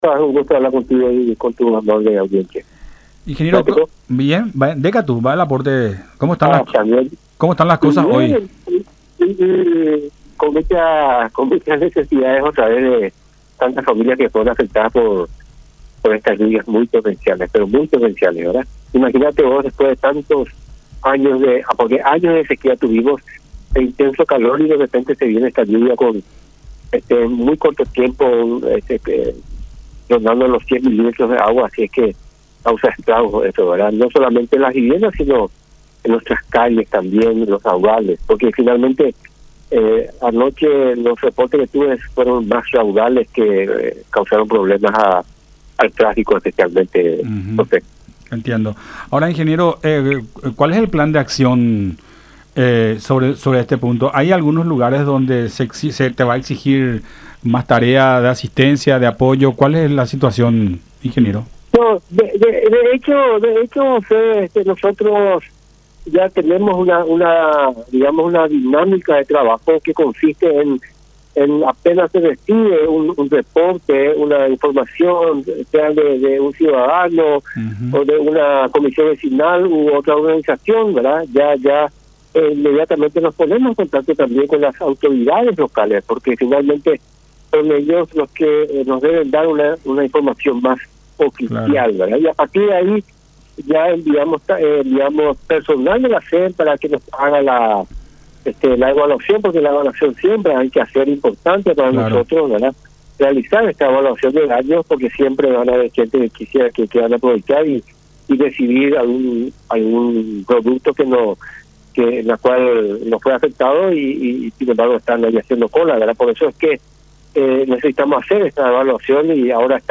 “Muchas familias fueron afectadas. Después de mucho tiempo de sequía, estamos teniendo estas torrenciales lluvias rondando los 100 milímetros de agua en tan poco tiempo. Estamos en la recolección de datos para enviar al personal y tener la evaluación de daños con mucha mayor precisión. Ahora estamos abocados a eso”, explicó Miguel Kurita, ministro interino de la SEN, en conversación con Nuestra Mañana por La Unión.